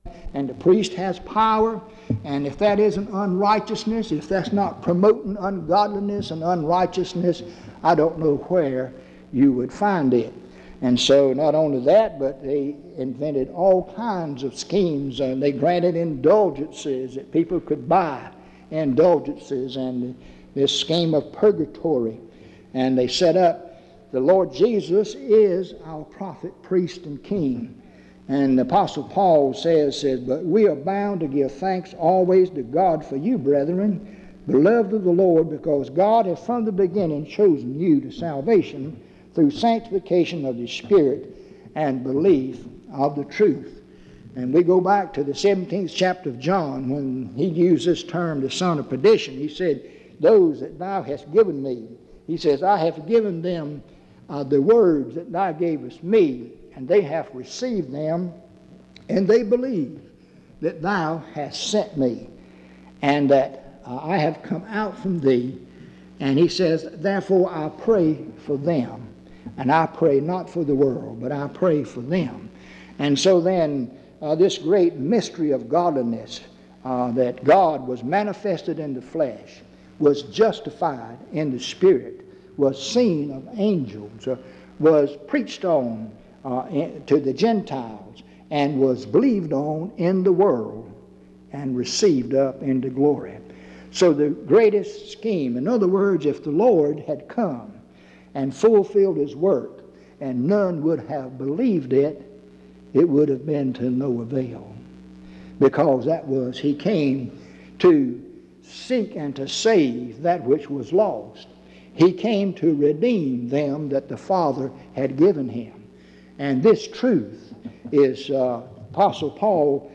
In Collection: Reidsville/Lindsey Street Primitive Baptist Church audio recordings Miniaturansicht Titel Hochladedatum Sichtbarkeit Aktionen PBHLA-ACC.001_043-B-01.wav 2026-02-12 Herunterladen PBHLA-ACC.001_043-A-01.wav 2026-02-12 Herunterladen